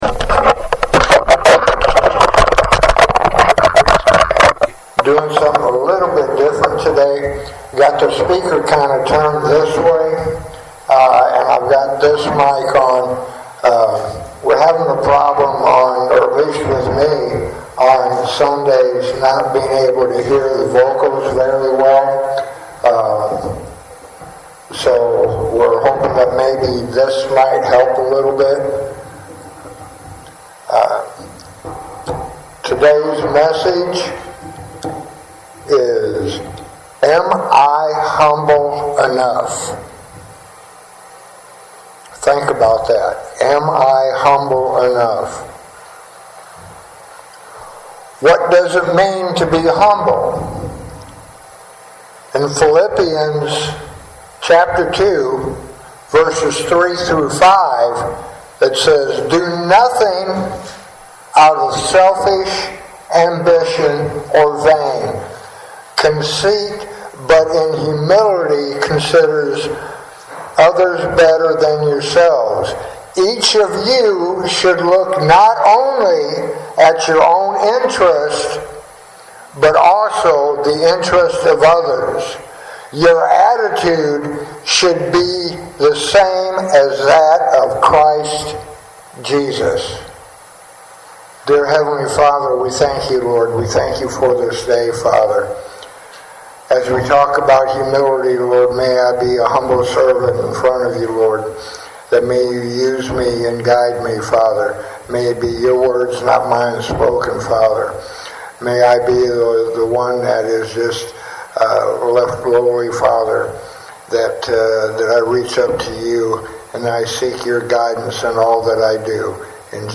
Sermons | Three Mile Wesleyan Church